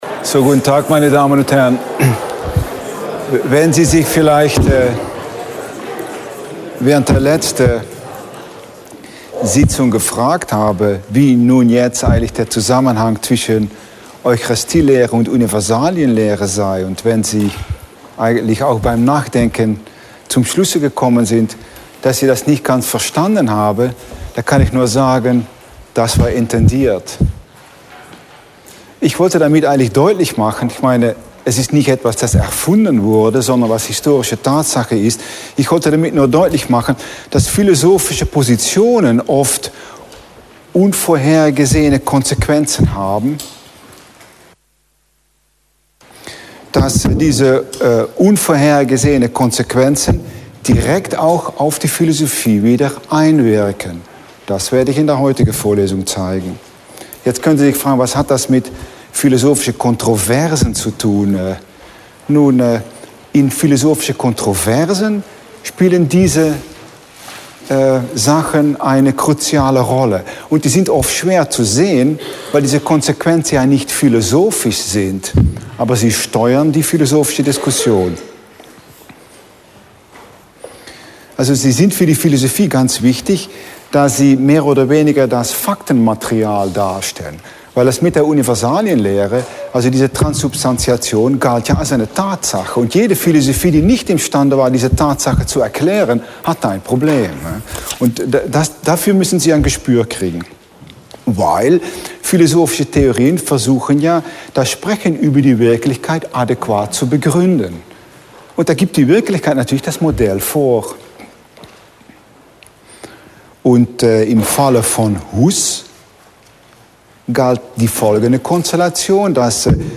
6. Vorlesung: 29.11.2010